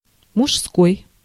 Ääntäminen
IPA : /ˈmeɪl/